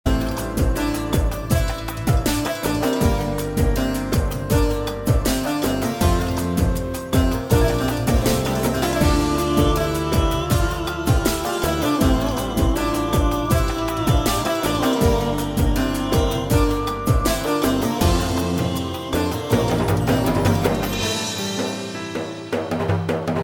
آهنگ زنگ بی کلام